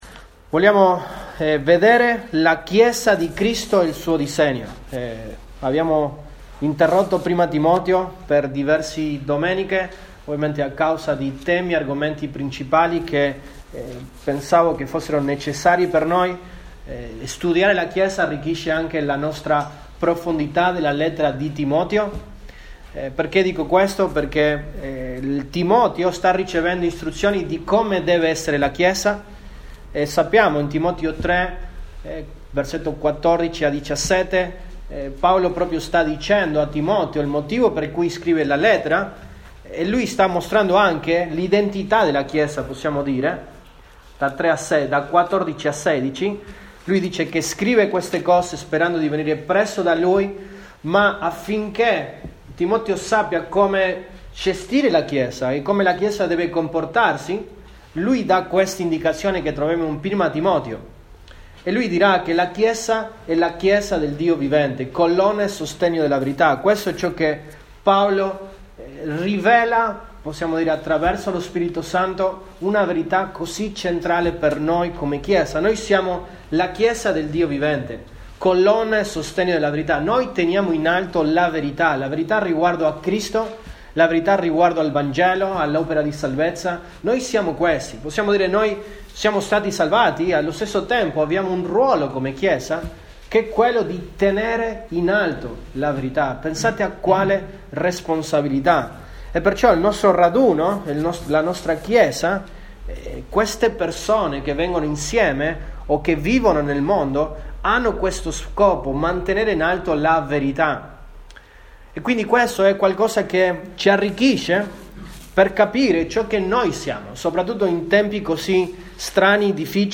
Sermoni